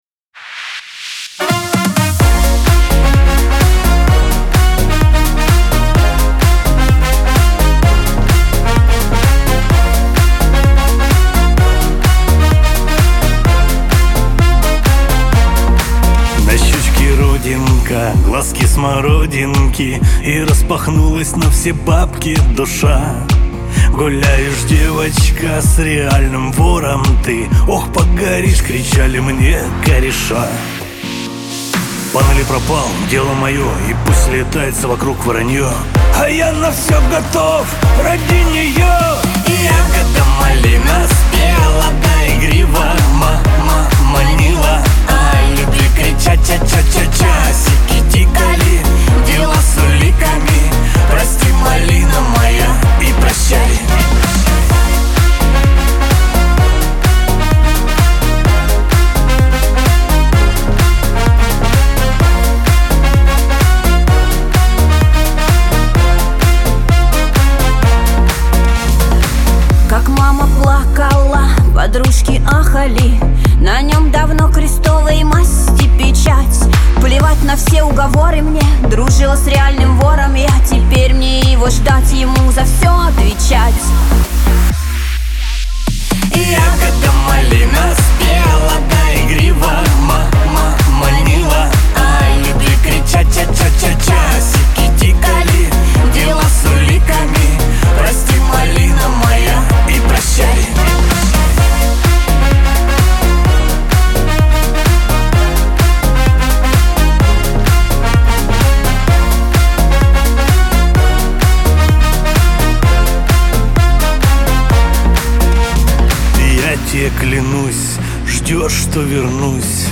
Шансон
дуэт , Лирика , грусть